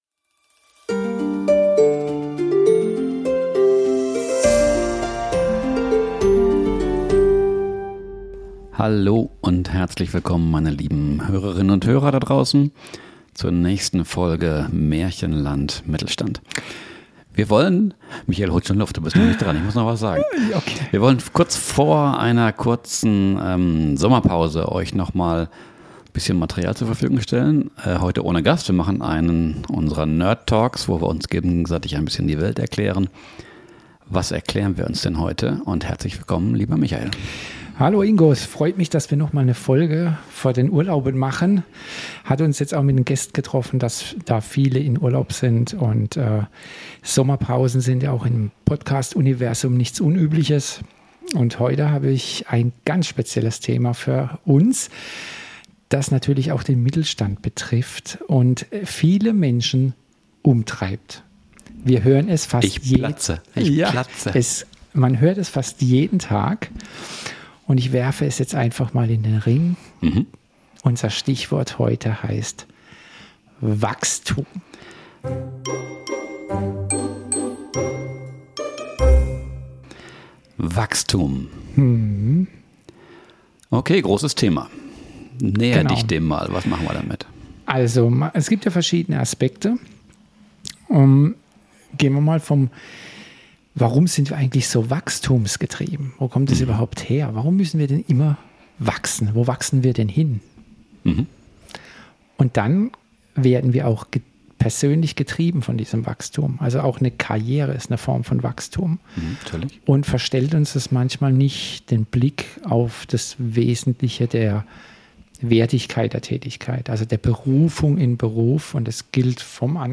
Ein Gespräch über CO₂-Rekorde, Billionäre, Tarifverträge, Coaching-Industrien, Sinnsuche und das Glück in Bhutan. Ehrlich, ungeschönt und streckenweise schonungslos.